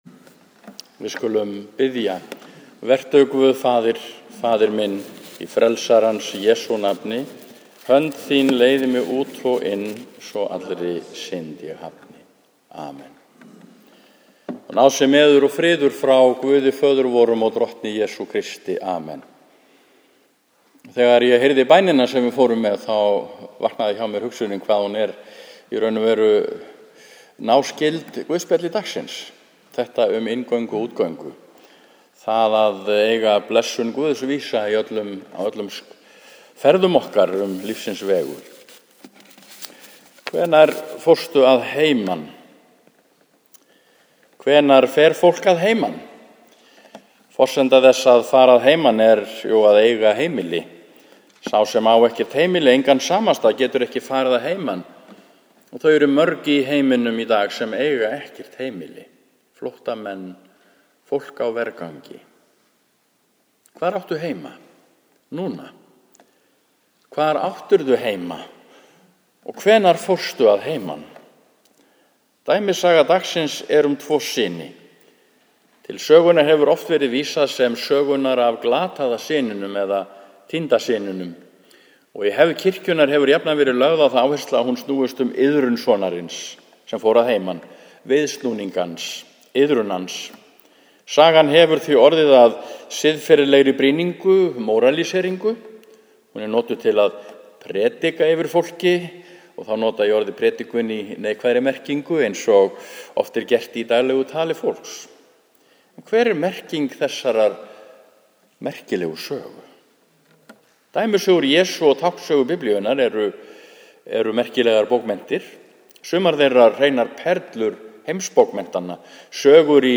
(Einhver innskot eða orðalagsbreytingar eru á hljóðupptökunni)